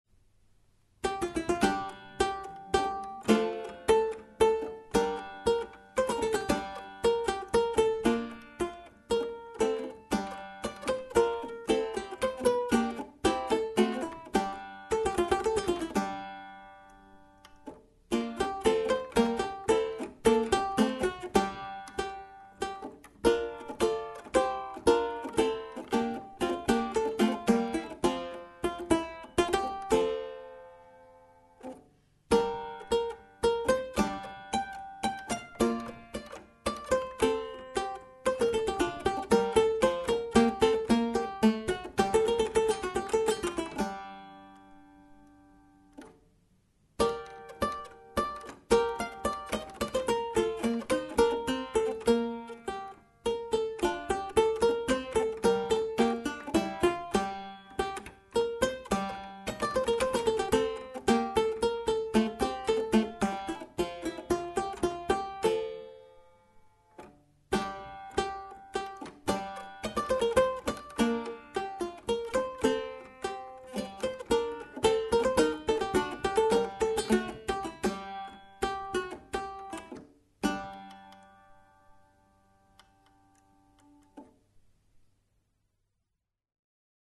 Clavicordo
CLAVICORDO-MitGanczemWillen.mp3